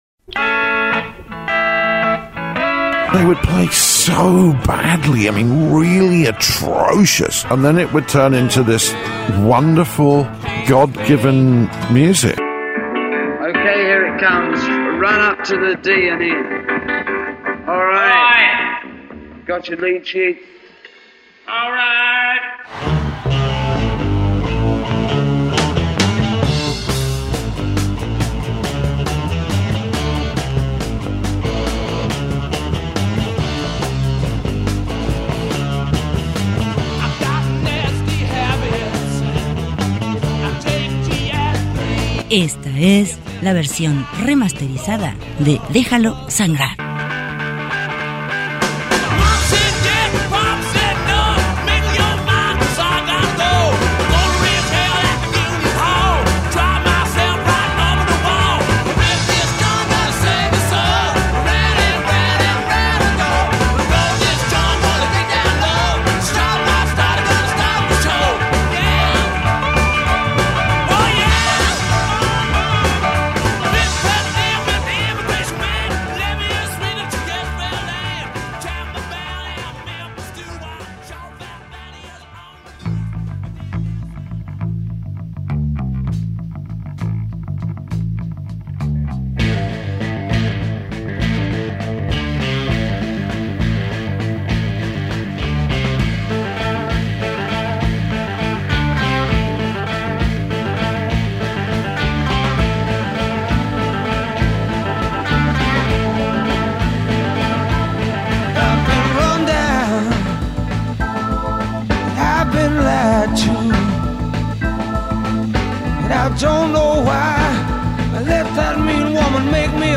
American Electric Blues 1965-1971